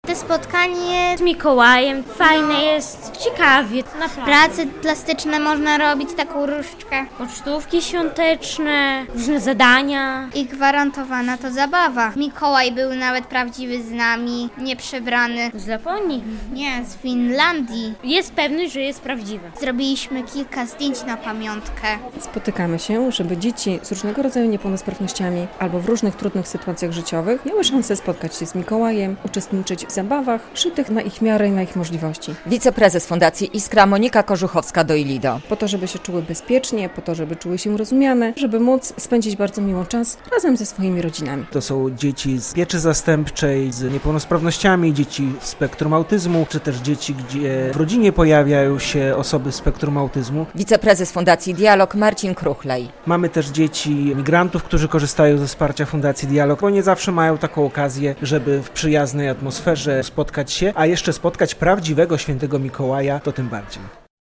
Impreza choinkowa Iskry i Dialogu - relacja